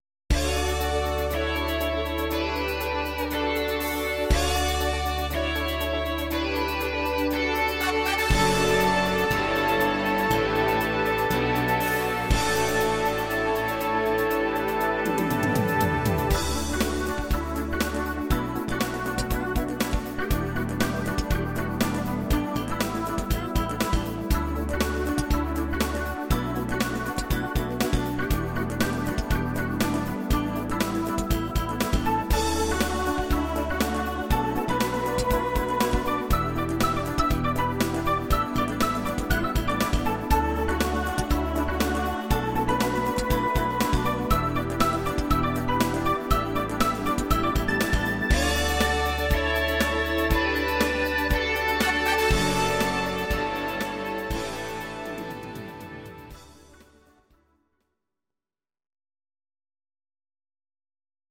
Audio Recordings based on Midi-files
Our Suggestions, Pop, 1980s